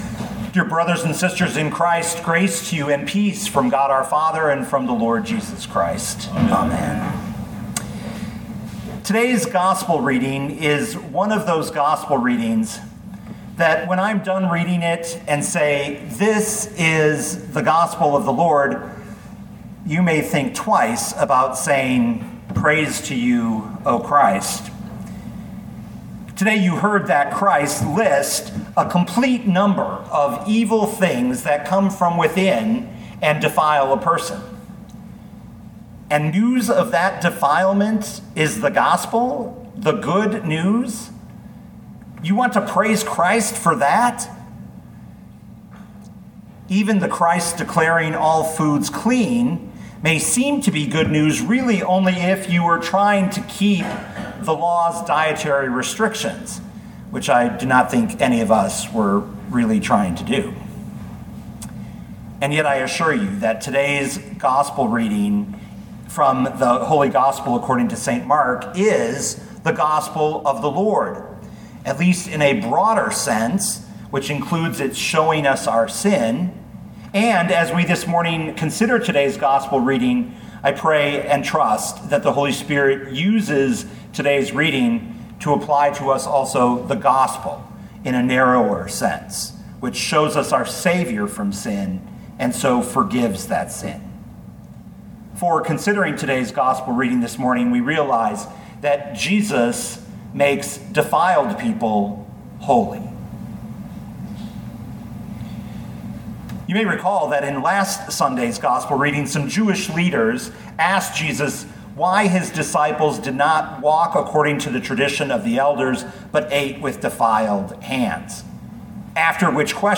2021 Mark 7:14-23 Listen to the sermon with the player below, or, download the audio.